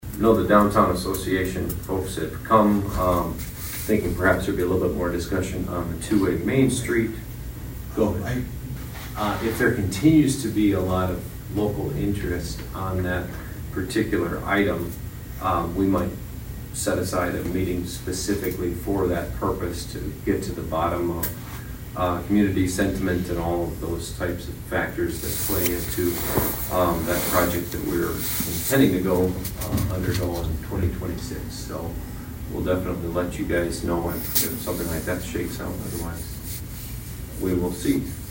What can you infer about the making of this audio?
ABERDEEN, S.D.(HubCityRadio)- On Monday night, the Aberdeen City Council did a work session to address the budget for the year 2026.